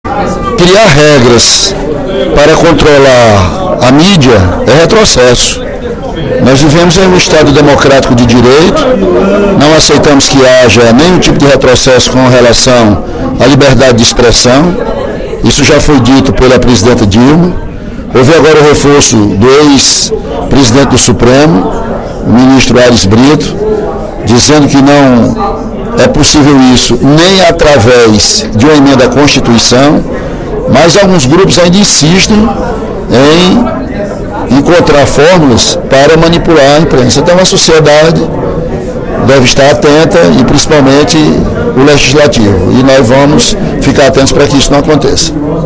O deputado Ely Aguiar (PSDC) destacou, durante pronunciamento no primeiro expediente da sessão plenária desta quinta-feira (06/12), o papel da imprensa como fiscalizadora dos três poderes da República.